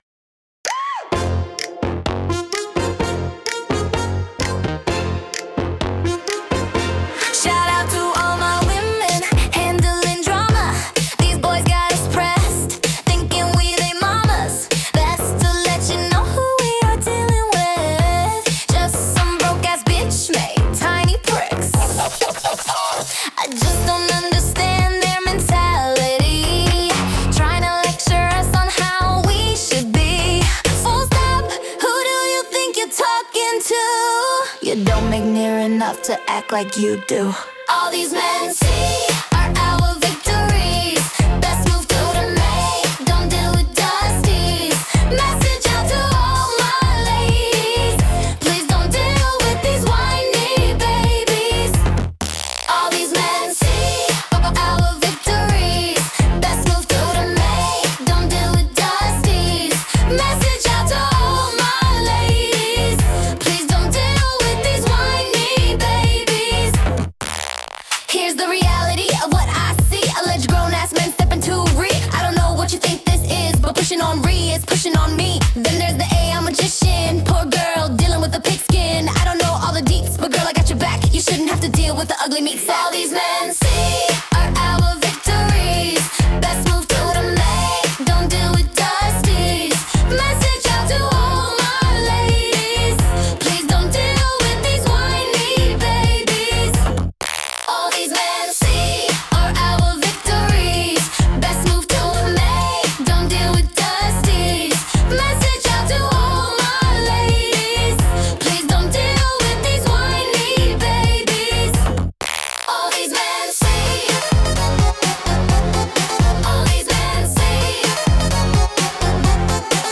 listen to this, which someone generated with the v4.5 engine.
Sounds like a real group.